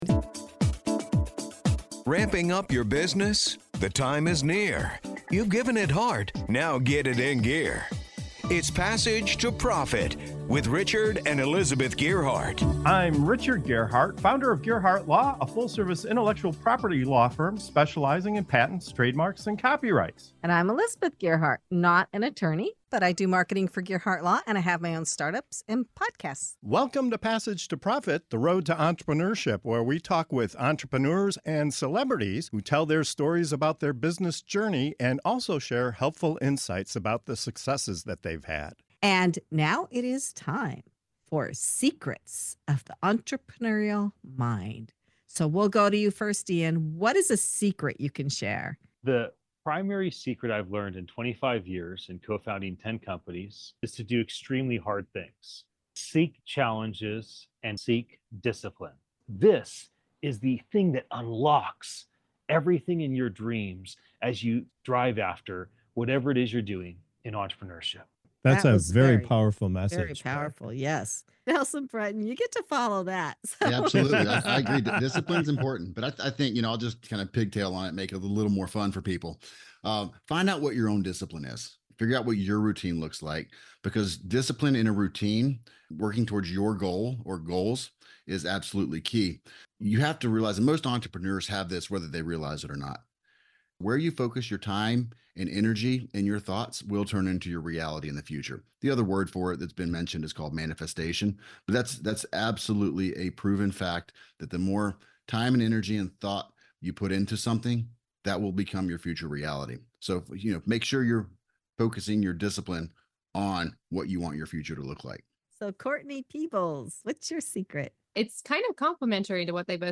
Discover why doing hard things, building daily routines, and even trading skills instead of spending money can be game-changers for any entrepreneur. It’s a fast-paced dose of real talk and actionable wisdom you won’t want to miss.